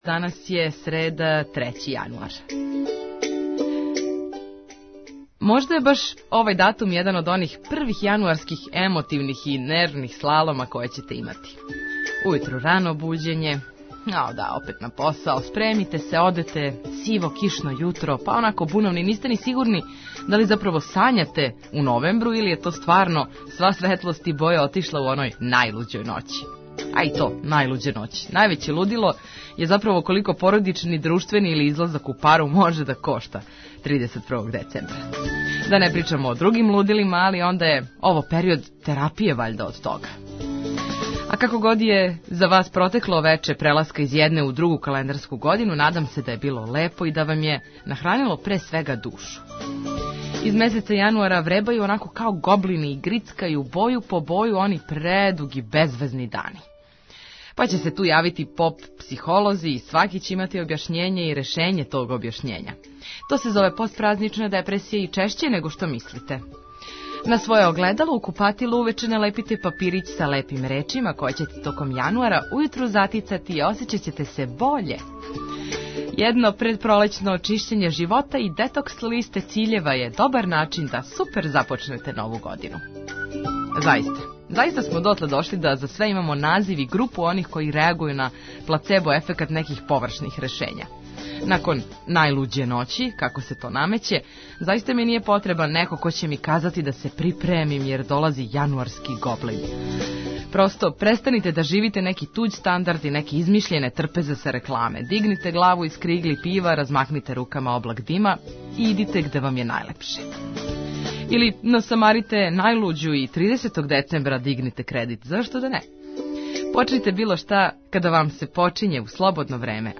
Први део празника је завршен, а уз битне информације и музику за добро расположење помоћи ћемо вам да се брзо вратите уобичајеним обавезама.